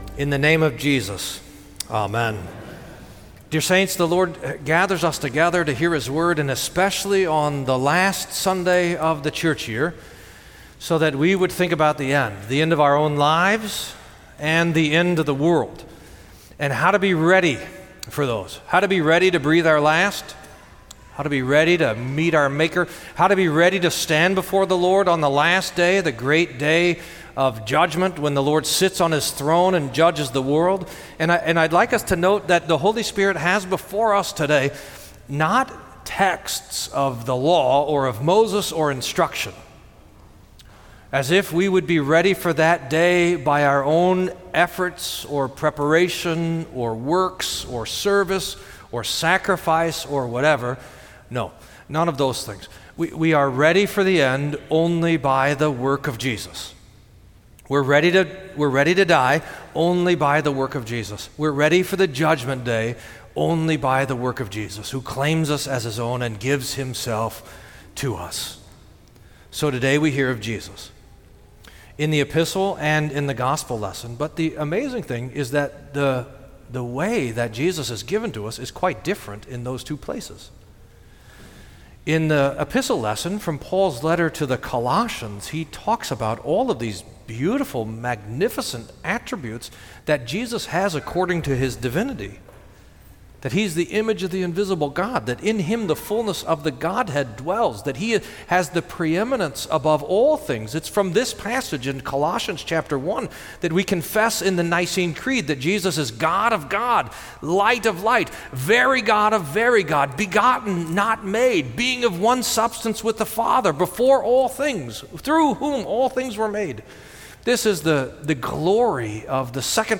Sermon for Last Sunday of the Church Year